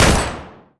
poly_shoot_pistol.wav